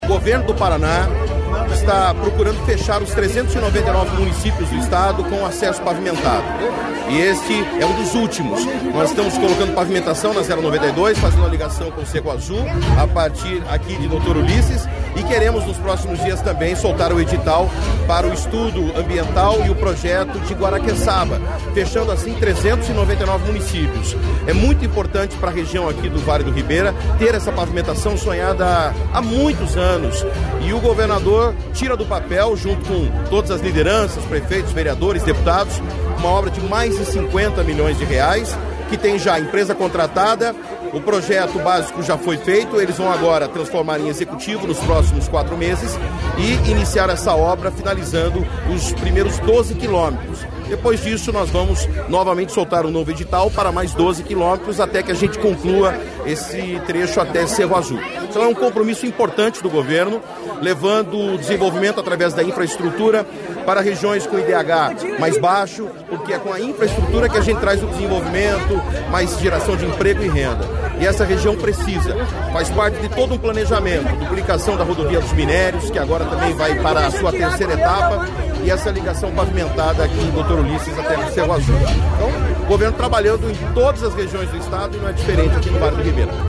Sonora do secretário Estadual da Infraestrutura e Logística, Sandro Alex, sobre a assinatura da ordem de serviço da pavimentação da PR-092 até Doutor Ulysses